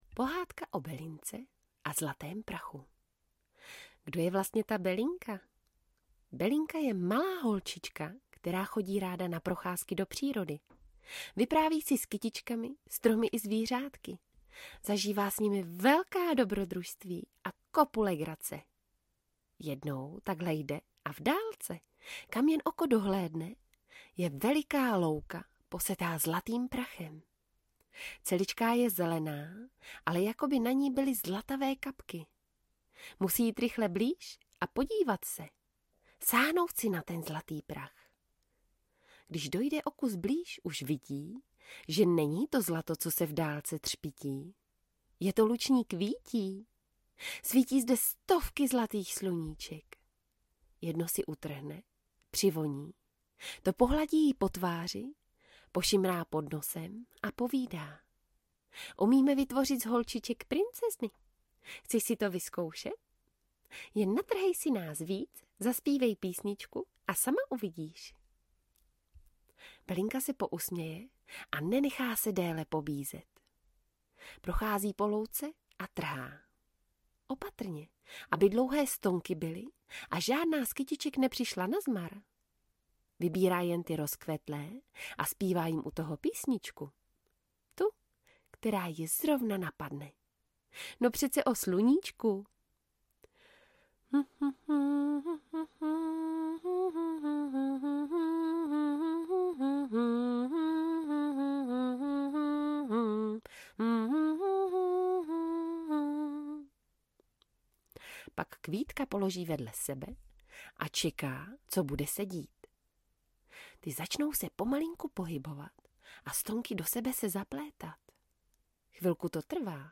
Belinčiny výlety audiokniha
Ukázka z knihy